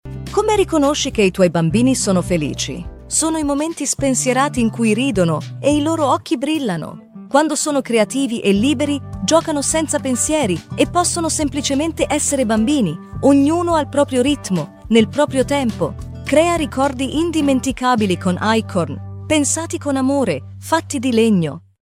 Qualität: Unsere KI-Stimmen liefern Ergebnisse, die kaum von menschlichen Sprechern zu unterscheiden sind.
KI Frau Italienisch:
KI-Frau-IT.mp3